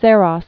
(sârŏs, särôs)